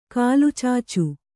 ♪ kālucācu